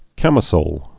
(kămĭ-sōl)